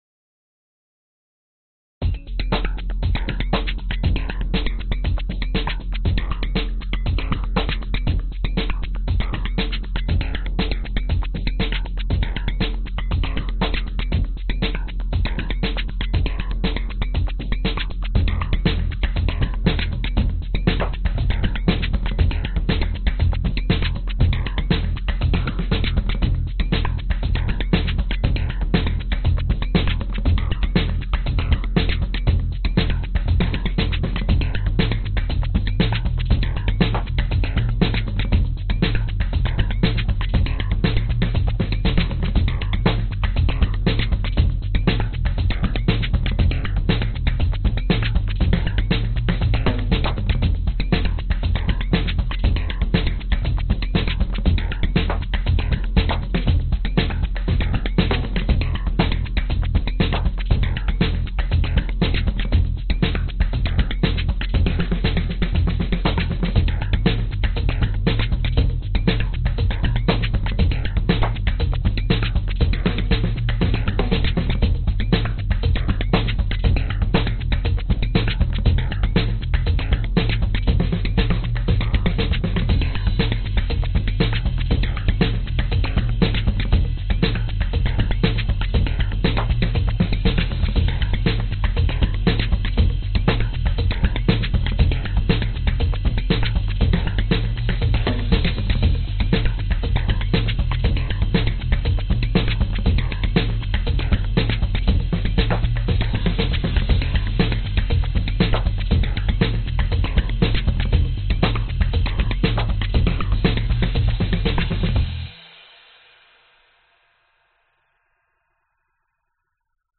描述：贝斯、鼓、打击乐、电子打击乐
Tag: 低音 打击乐 电子打击乐 器乐 电影音乐